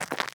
Ice Footstep 1.ogg